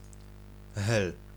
Hel Peninsula (Polish pronunciation: [xɛl]